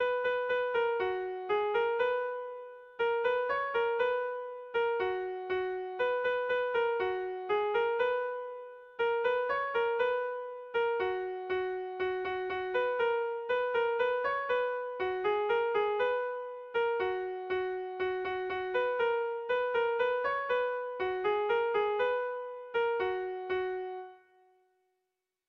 Haurrentzakoa
Kopla handia